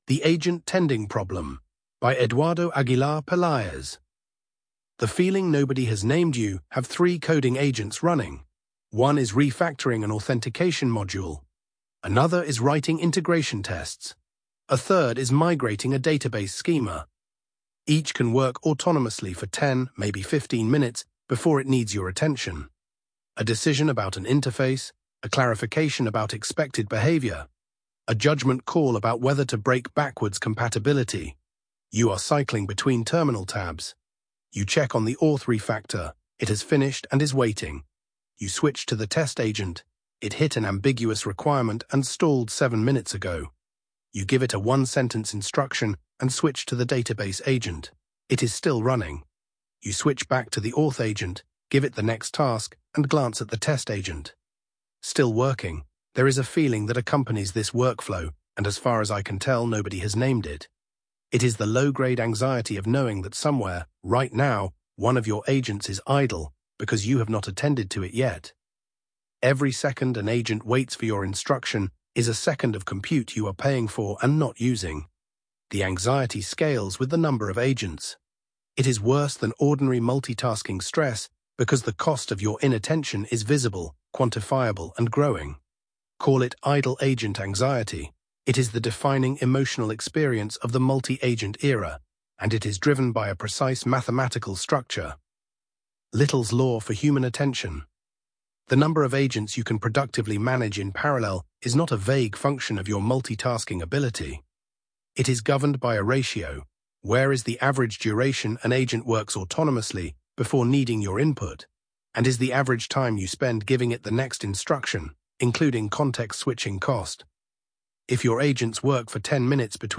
Narrated by George via ElevenLabs